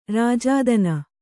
♪ rājādana